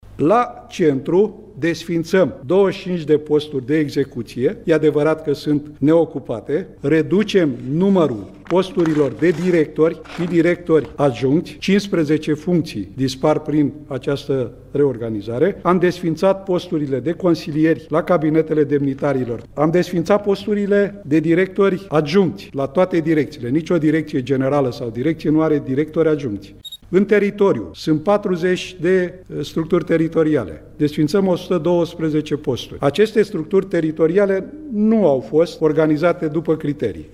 Președintele Autorității, Adrian Țuțuianu, a explicat, în cadrul unei conferințe de presă, câte posturi sunt desființate la București și în restul țării